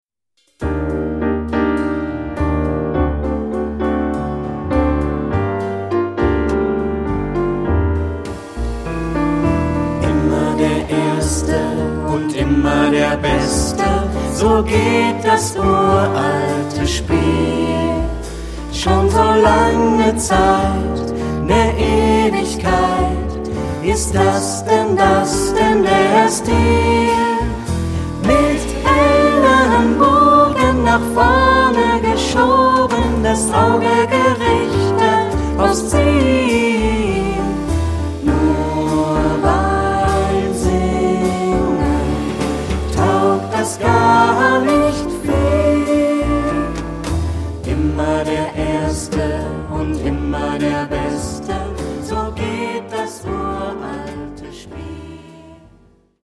Ad libitum (4 Ad libitum Stimmen).
Kanon. Choraljazz.
jazzy ; fröhlich ; leicht
Tonart(en): g-moll